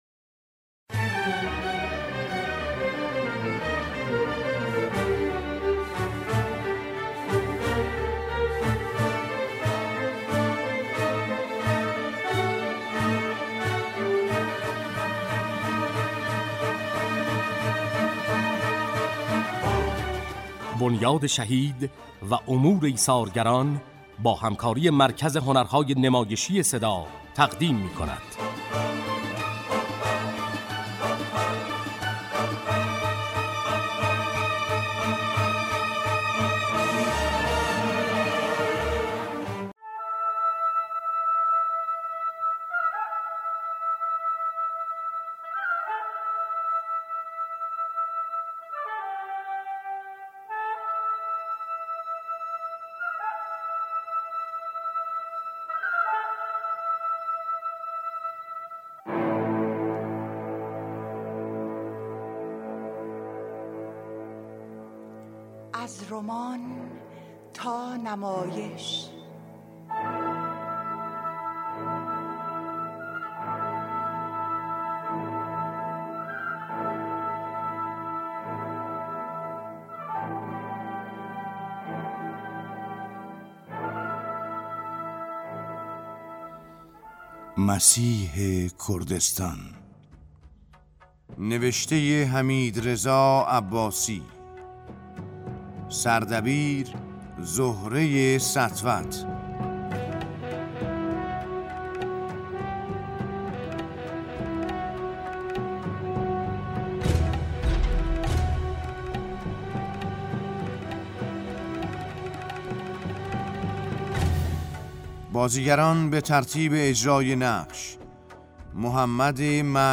نمایش رادیویی مسیح کردستان نوشته حمیدرضا عباسی، نمایشی زیبا از زندگی سردار شهید محمد بروجردی می باشد که با بیان شیرین گویندگانی توانمند به بازگویی زوایای مختلف زندگی این بزرگمرد می پردازد.